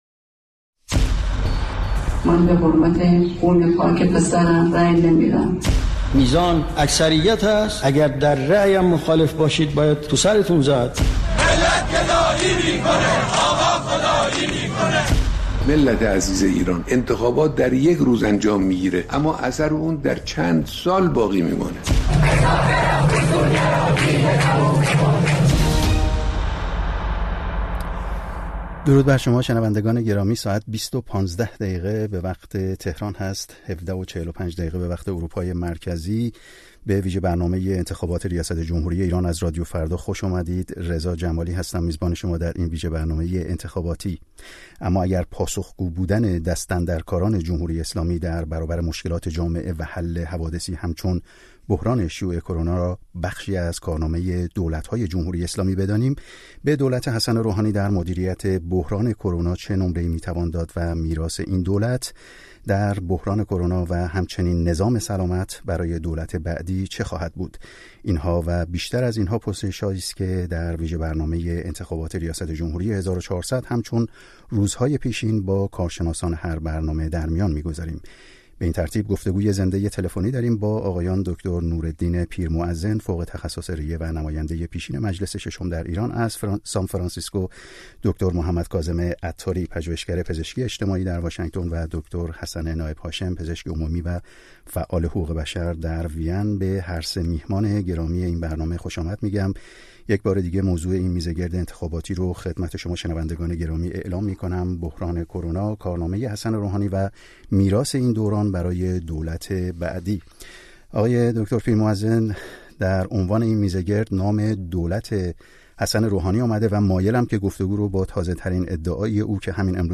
میزگرد ویژه انتخابات: بحران کرونا، کارنامه روحانی و میراث آن برای دولت بعد